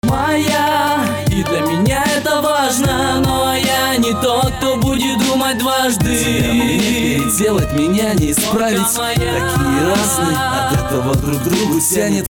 • Качество: 320, Stereo
мужской вокал
лирика
русский рэп